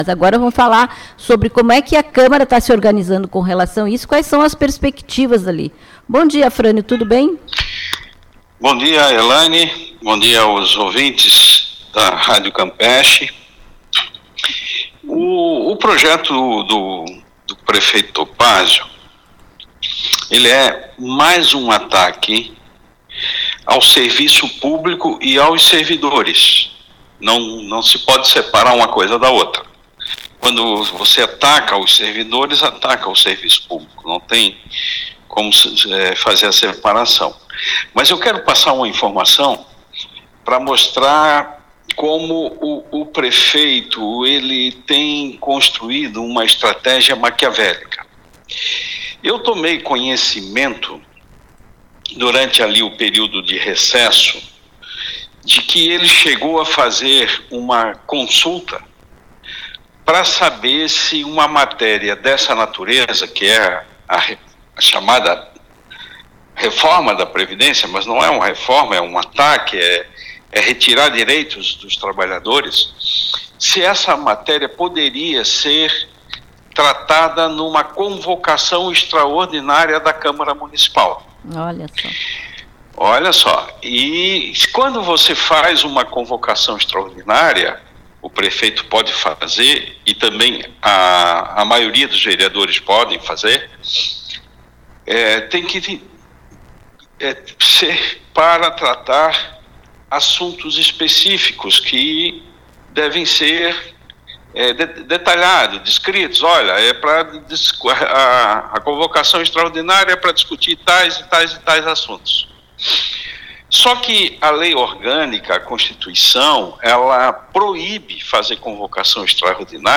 Trabalhadores da prefeitura de Florianópolis estão em greve por conta de o prefeito não ter cumprido acordo da greve passada e também na luta contra a reforma da previdência que retira ainda mais direitos. Conversamos no Programa Campo de Peixe com o vereador Afrânio Boppré, do Psol, e ele fala das perspectivas deste projeto que já está na Câmara para discussão e votação.